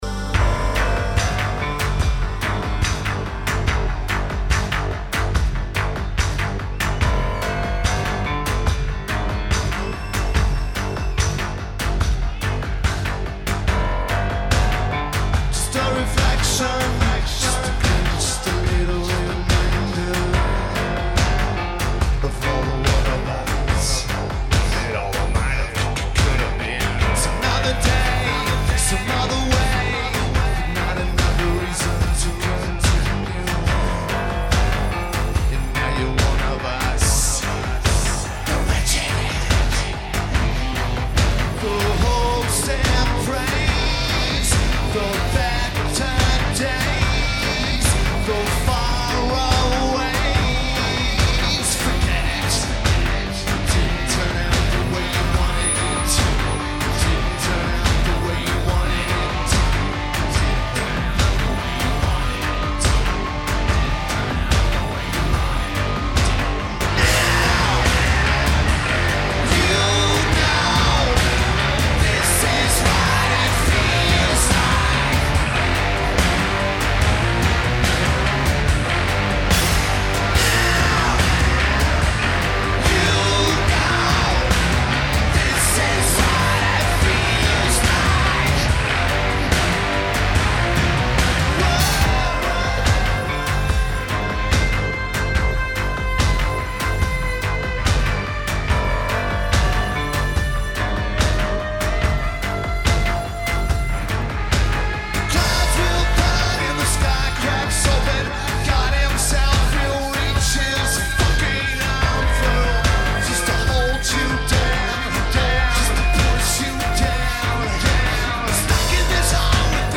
Forestglade Festival
Lineage: Audio - PRO (Pre-FM Mix)